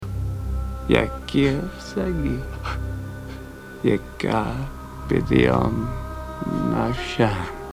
Tags: The Passion Of Christ The Passion Passion movie clips Mel Gibson film Aramaic audio